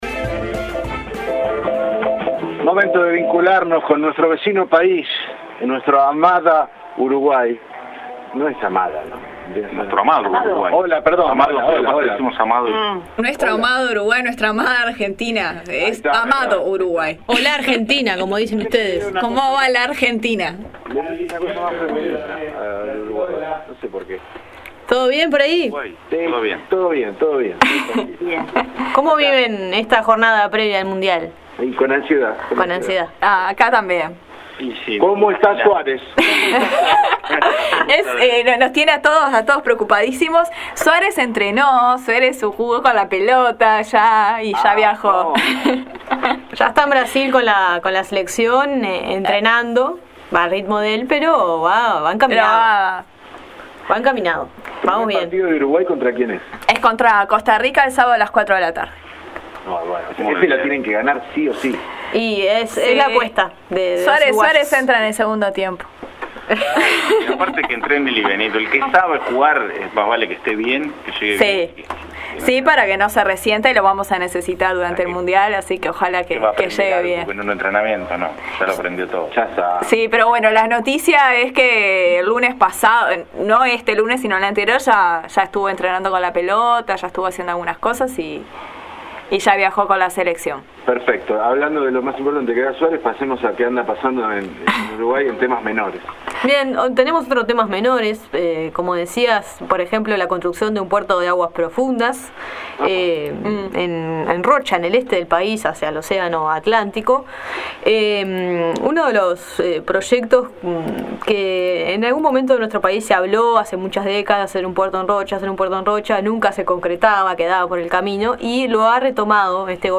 En otro dúplex con los colegas de No Se Sabe de Radio Universidad Nacional de La Plata, hicimos la previa al Mundial donde las dos selecciones argentina y uruguaya ya se encuentran en Brasil y juegan este fin de semana su primer partido.